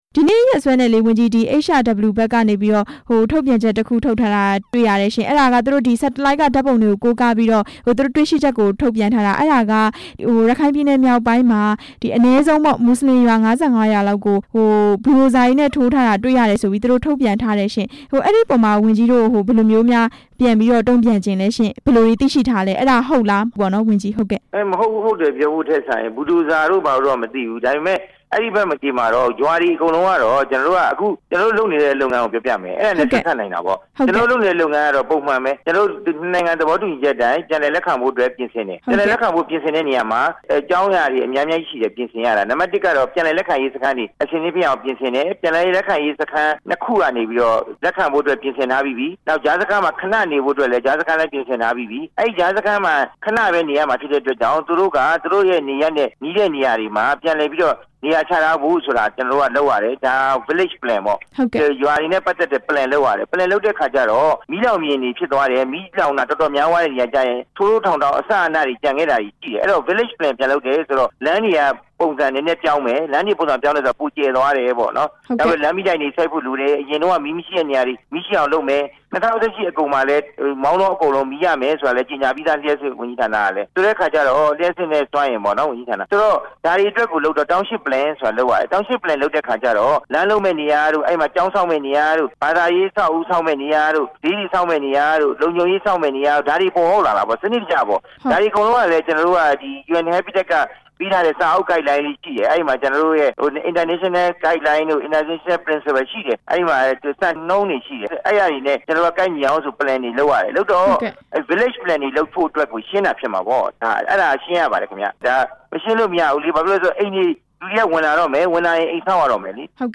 HRW ထုတ်ပြန်ချက်အကြောင်း ဒေါက်တာဝင်းမြတ်အေးနဲ့ ဆက်သွယ်မေးမြန်းချက်